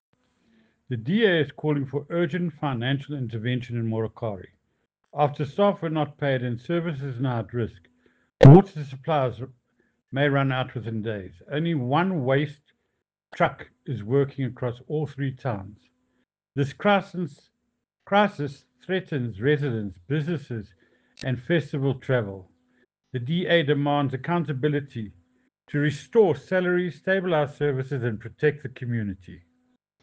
English soundbite by Cllr Ian Riddle,